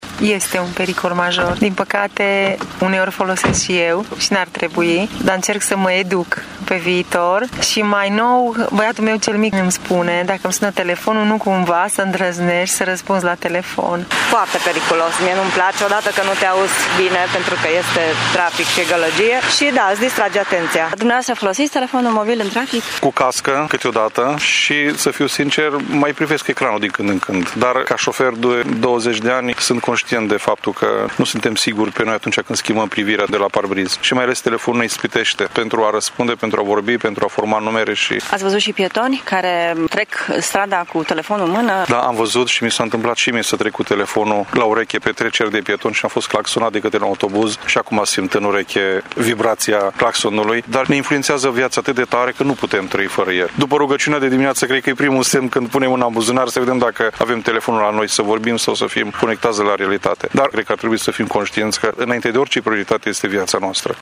Târgumureșenii recunosc că acestea sunt practici periculoase, însă, nu se pot abține să nu fie în legătură permanentă cu telefonul mobil: